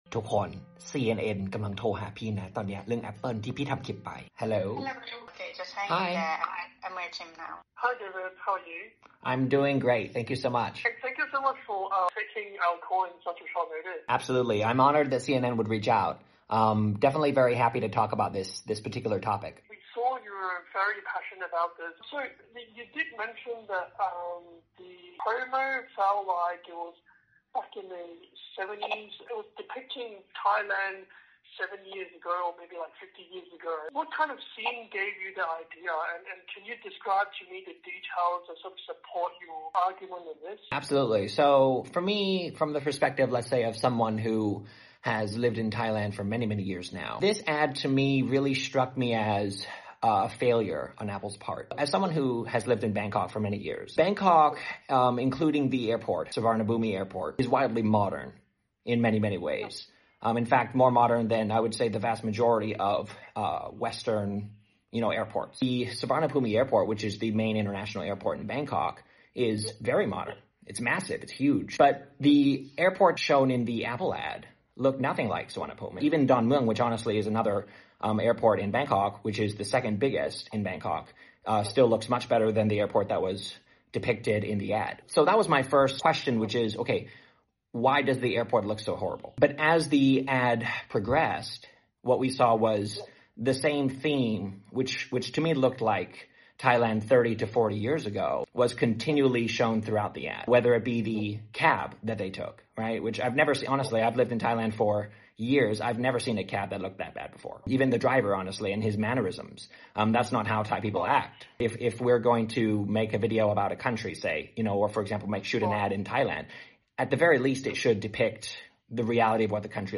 บทสัมภาษณ์ของพี่กับ CNN ในประเด็นของ Apple #ข่าว